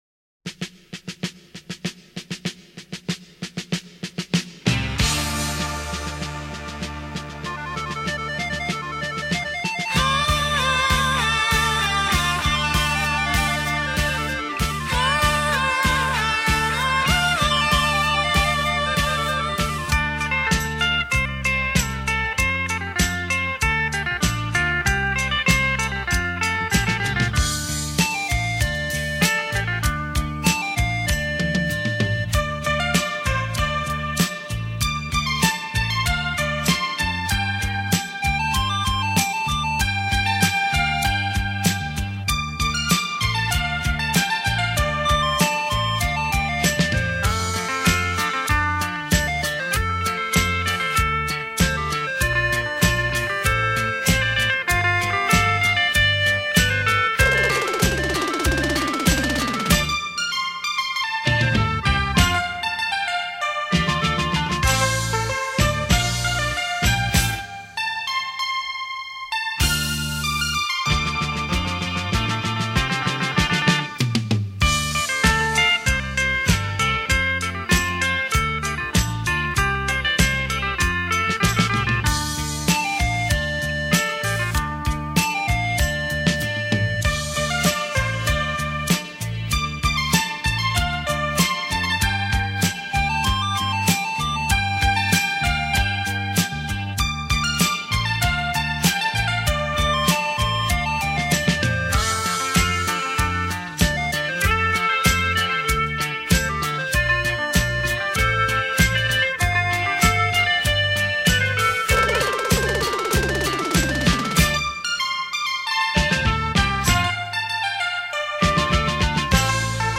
史上最强720度环绕立体声
360度超炫立体音效玩弄音乐于股掌